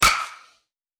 Bat Hit Wood Crack.wav